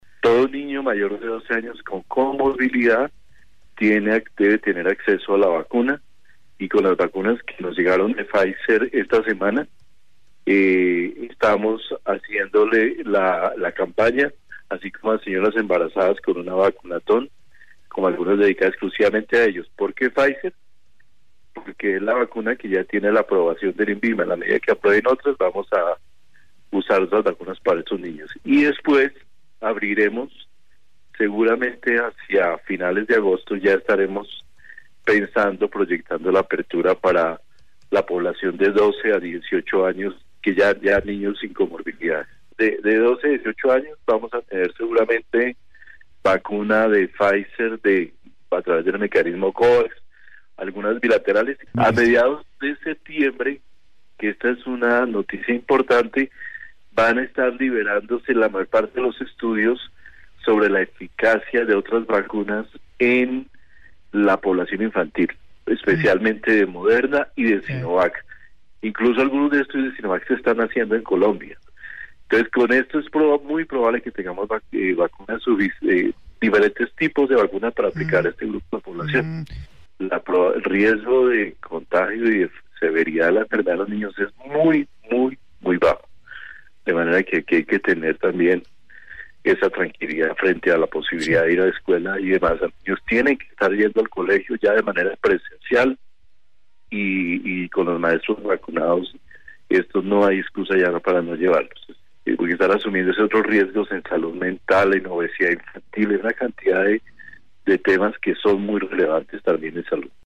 Audio ministro de Salud y Protección Social, Fernando Ruiz Gómez.